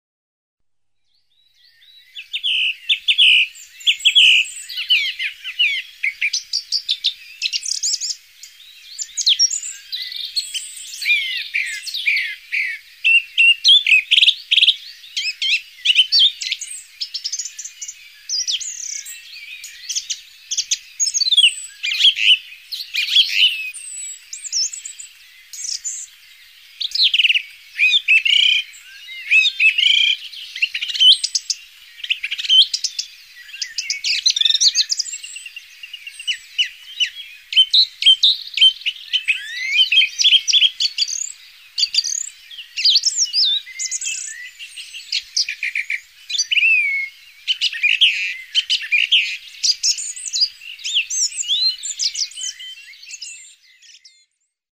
Cants d'espècies habituals a Cataluya
Tord (Turdus philomelos)